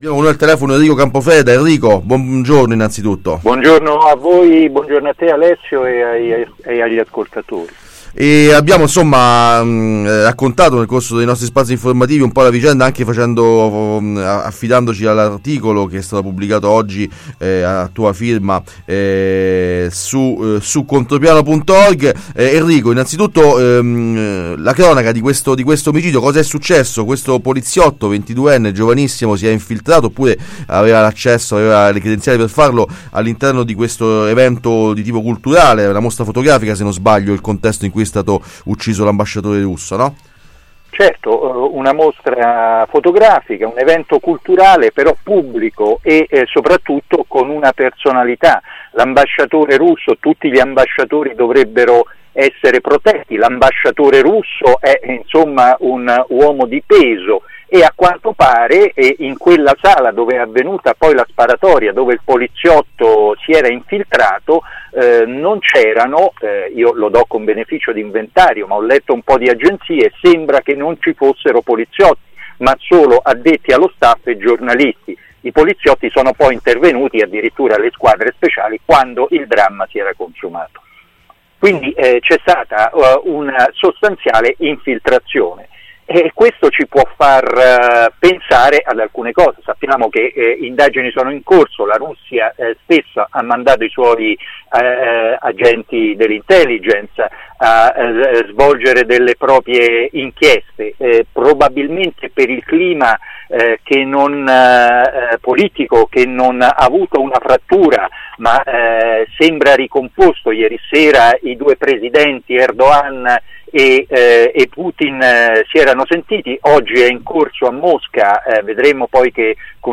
Omicidio ambasciatore russo: intervista